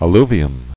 [al·lu·vi·um]